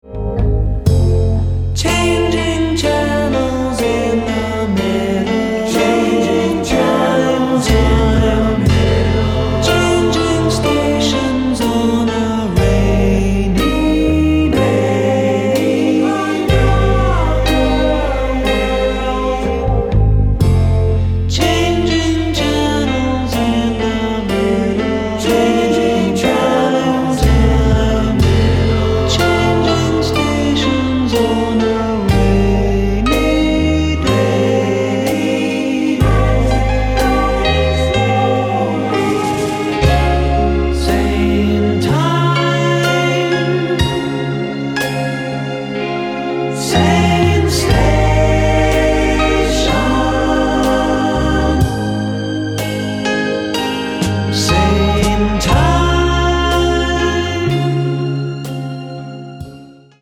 Lead Vocal, Keyboards & Percussion.
Guitars & Vocal.